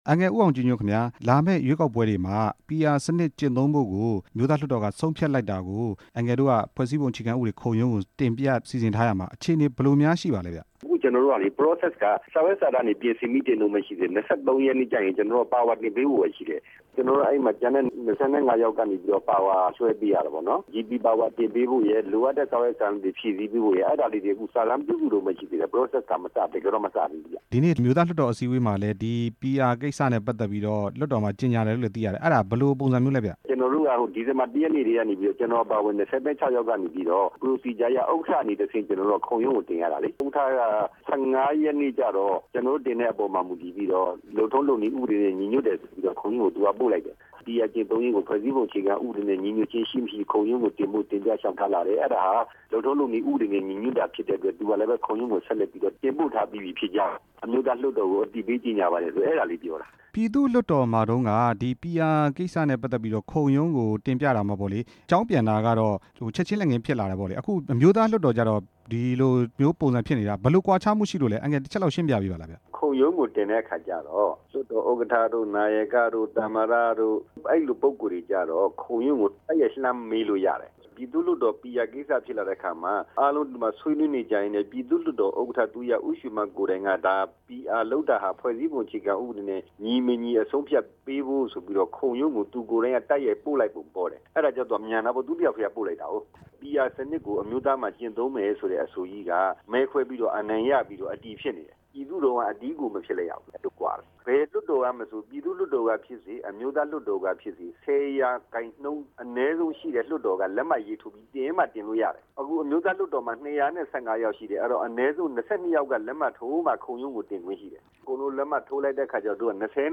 ဦးအောင်ကြည်ညွန့်ကို မေးမြန်းချက်